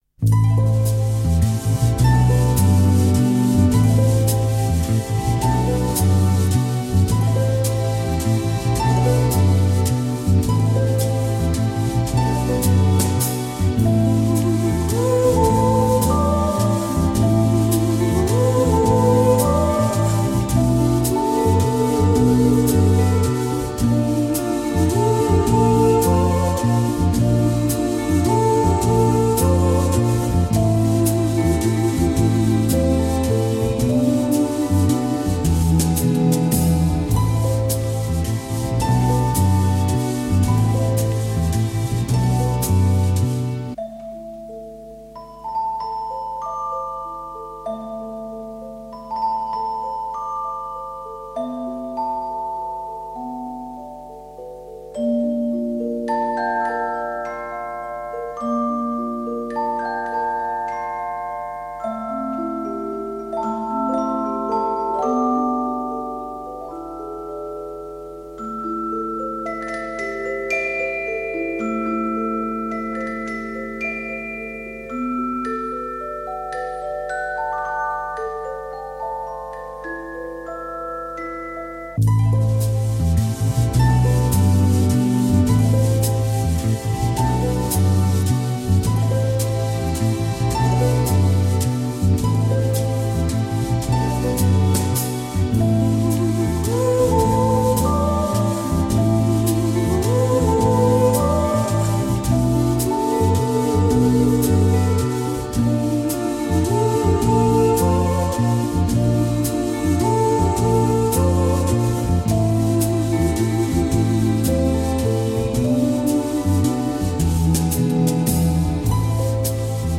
radiomarelamaddalena / STRUMENTALE / ORCHESTRE /
Original Motion Picture Soundtrack